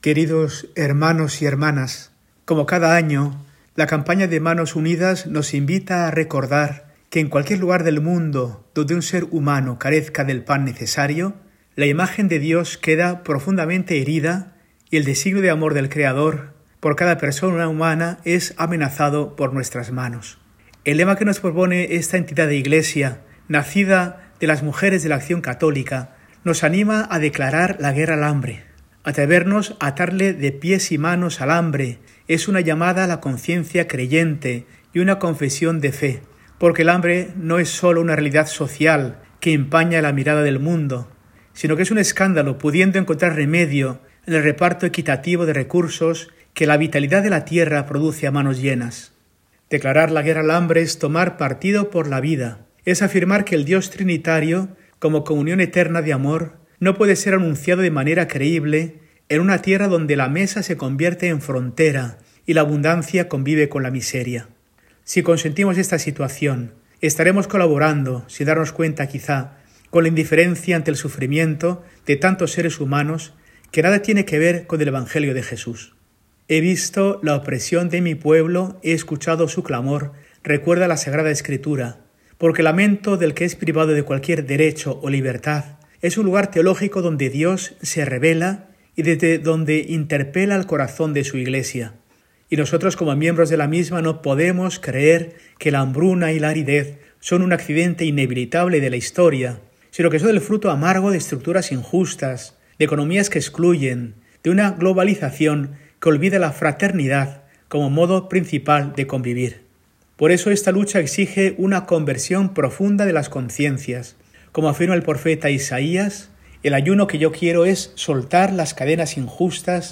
Mensaje semanal de Mons. Mario Iceta Gavicagogeascoa, arzobispo de Burgos, para el domingo, 8 de febrero de 2026, V del Tiempo Ordinario y Campaña contra el Hambre de Manos Unidas